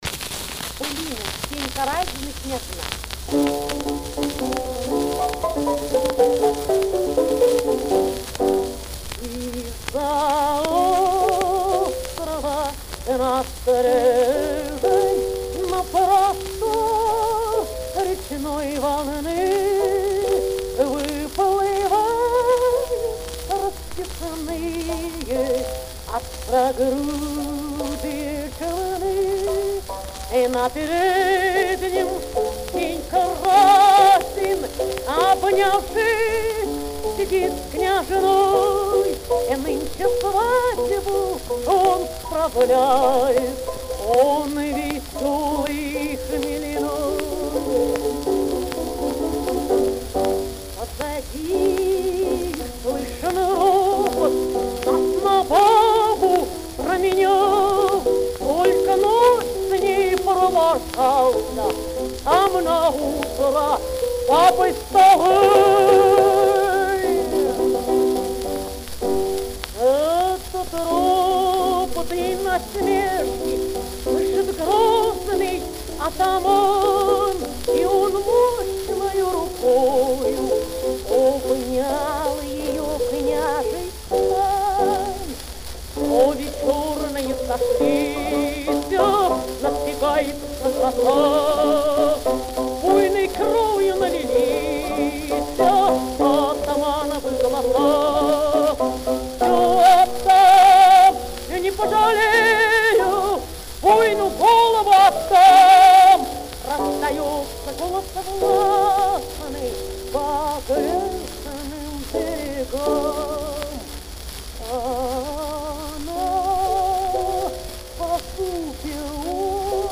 Каталожная категория: Меццо-Сопрано
Жанр: Народная песня
Исполнитель: Надежда Васильевна Плевицкая
Вид аккомпанемента: Фортепиано
Место записи: Москва
Другая мелодия, чем та, что известна нам.
nadejda-vasilevna-plevitskaya-1911.mp3